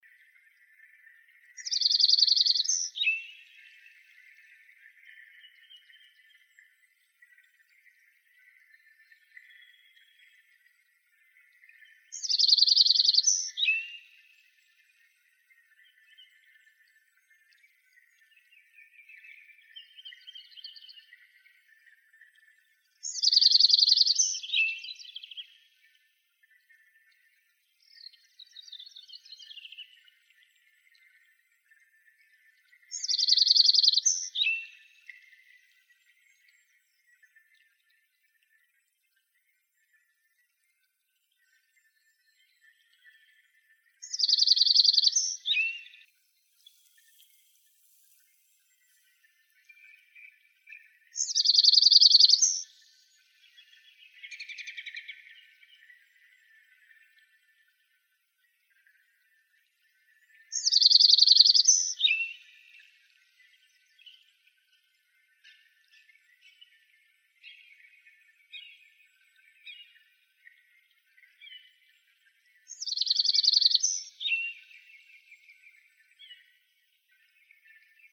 lincolnssparrow.wav